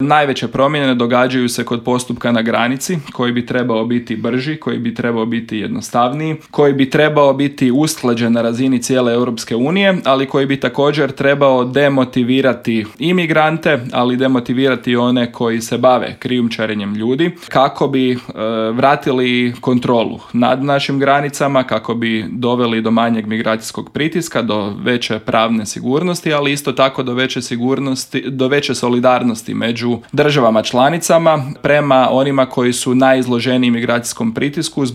Više detalja o Paktu, kako će se odraziti na Hrvatsku, ali i o kritikama na postignuti dogovor u intervjuu Media servisa razgovarali smo s eurozastupnikom iz redova HDZ-a, odnosno EPP-a Karlom Resslerom.